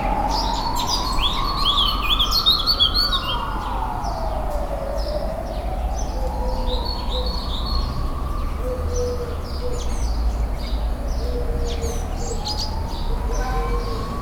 birds singing in garden 9.wav
A blackbird recorded in a city garden with a Tascam DR 05.
.WAV .MP3 .OGG 0:00 / 0:14 Type Wav Duration 0:14 Size 2,39 MB Samplerate 44100 Hz Bitdepth 1411 kbps Channels Stereo A blackbird recorded in a city garden with a Tascam DR 05.
birds_singing_in_garden_9_ffb_j73.ogg